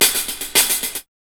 4304L HH-PAT.wav